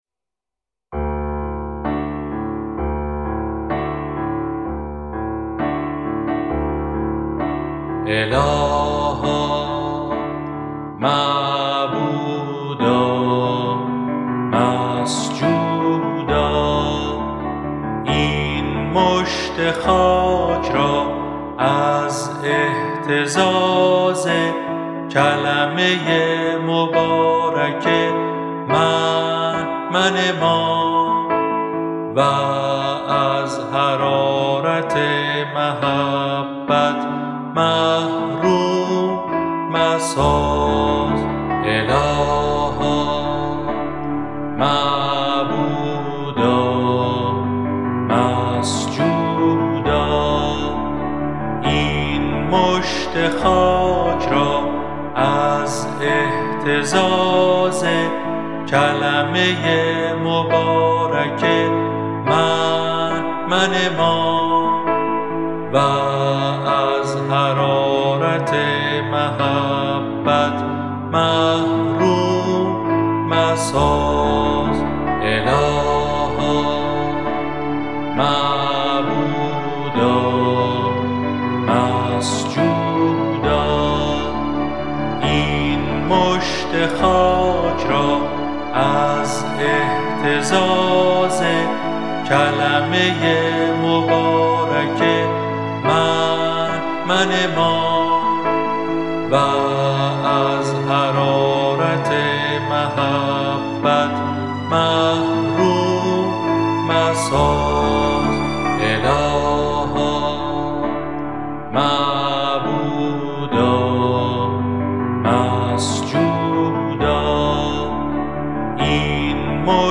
اذکار فارسی (آوازهای خوش جانان)